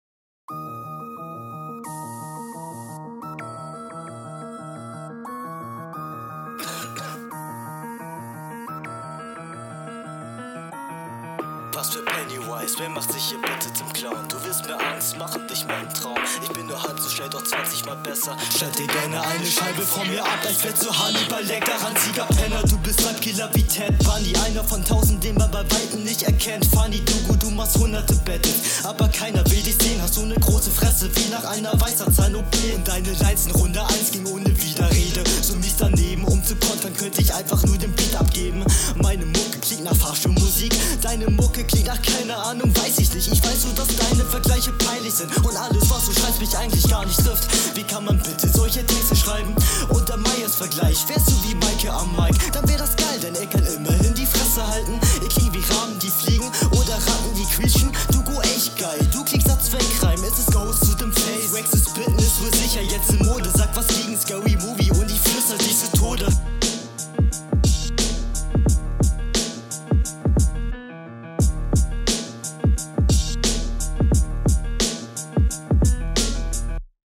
Beim Intro dachte ich: "Geil jetzt kommt gleich ne dick abgemischte stimme". War etwas enttäuscht …